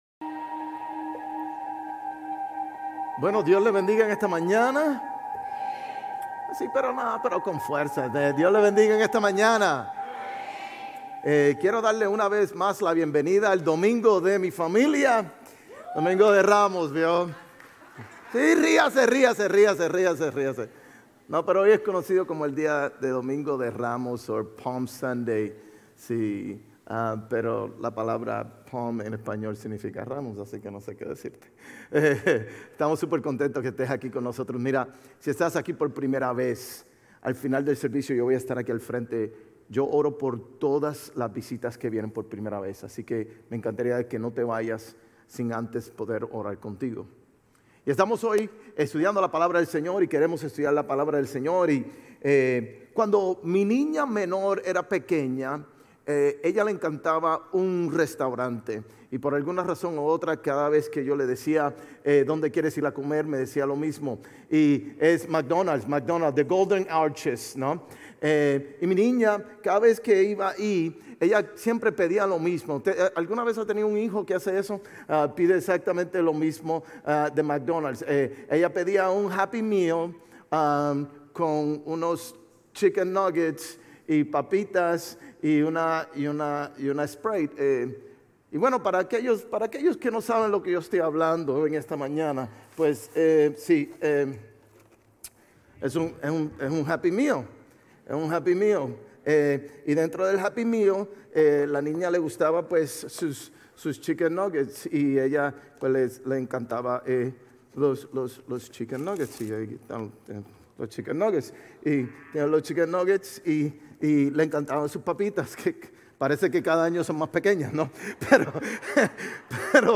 Sermones Grace Español 4_13 Grace Espanol Campus Apr 14 2025 | 00:36:21 1x 00:00 / 00:36:21 Subscribe Share RSS Feed Share Link Embed